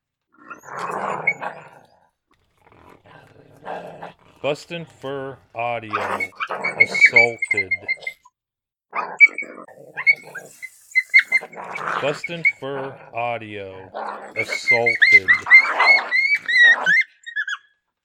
BFA’s Cash and Bobby fighting over food, Female Bobby is the aggressor and Cash takes the whipping.
• Product Code: pups and fights